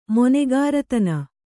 ♪ monegāratana